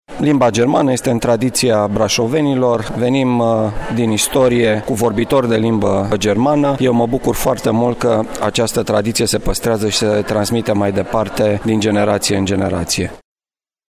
Prefectul județului Brașov, Marian Rasaliu: